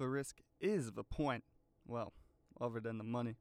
Voice Lines